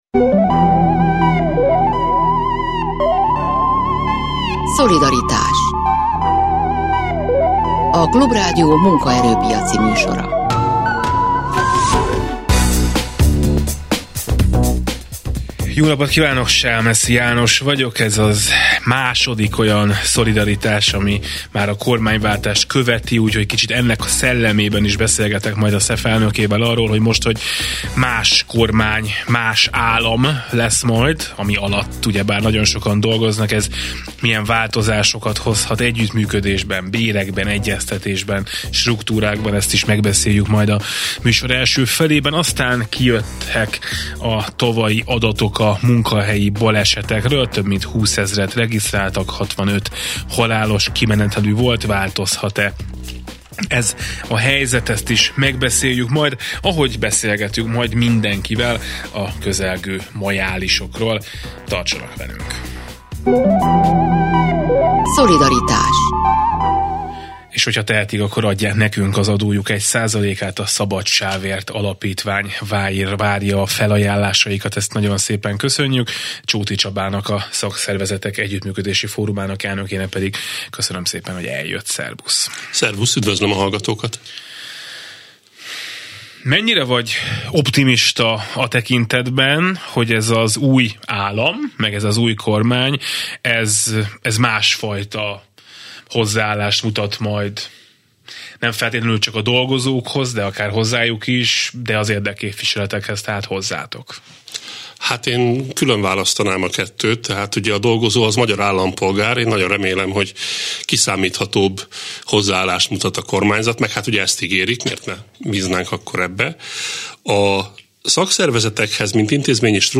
Új kormány, új lehetőségek - interjú a Klub Rádióban